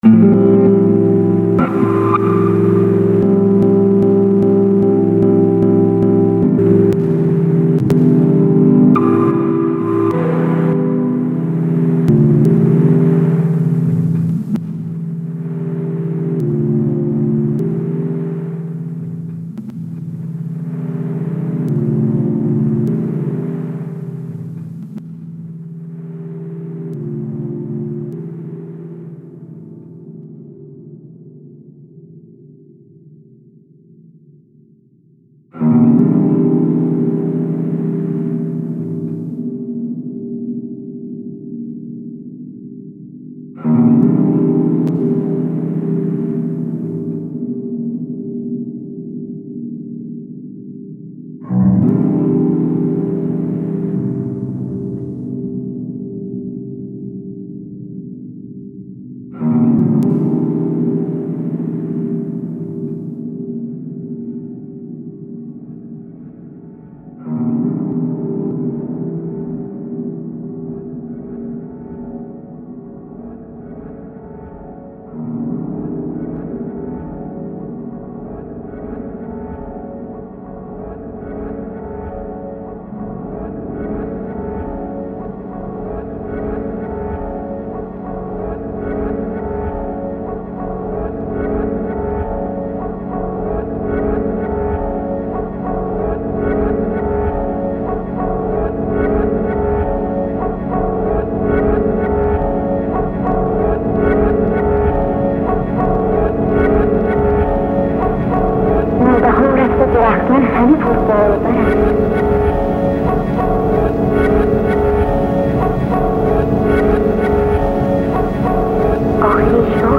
a boy / girl duet experimenting with samples and stuff